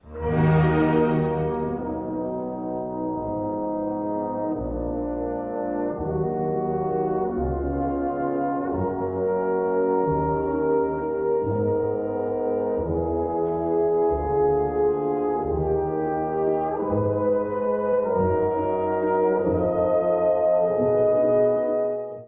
古い音源なので聴きづらいかもしれません！（以下同様）
第4楽章｜宗教風、厳かな音楽
トロンボーンが加わり、一気に荘厳になります！
第二部では主題がカノン風に扱われ、第三部では音楽はどんどん壮麗になり——
オルガン風の和声が響くうちに終結します。